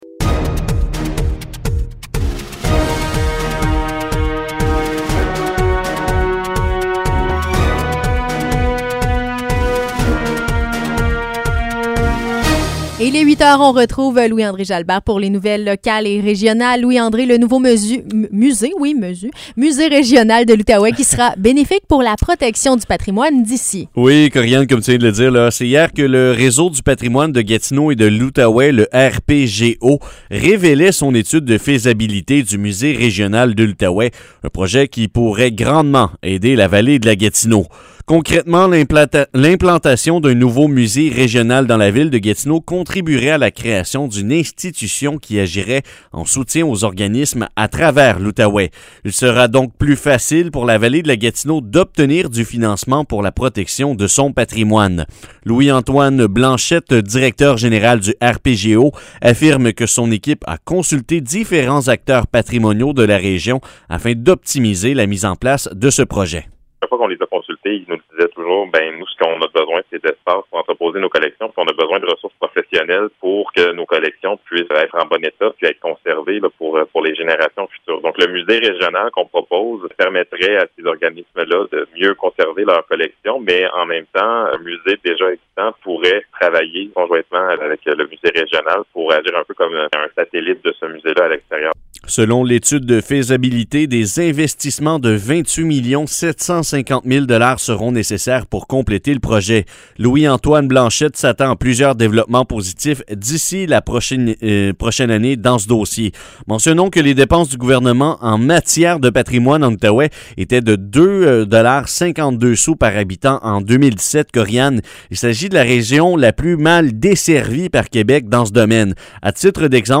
Nouvelles locales - 26 février 2021 - 8 h